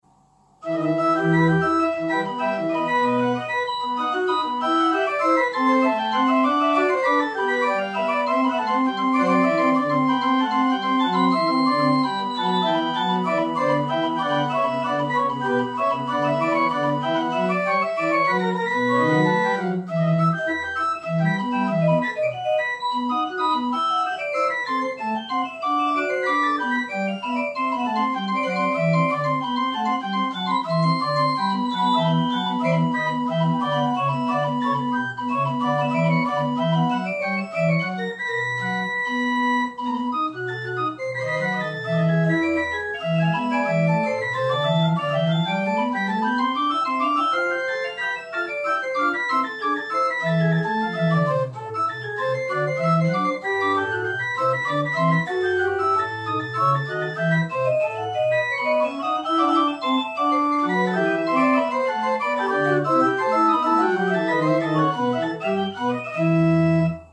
un petit orgue unit en cours de remise en état
du type à dédoublement (unit-organ), comportant deux rangs de 4': principal, et flûte ouverte, auxquels s'ajoute une octave de bourdon 8,
pour donner 7 jeux par plan sonore (+ tierce sur II)